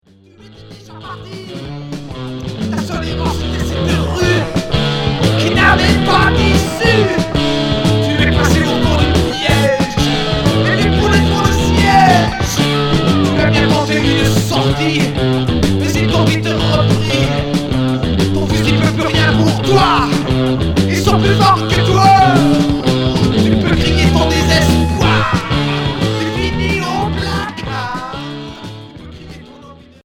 Rock punk Unique 45t retour à l'accueil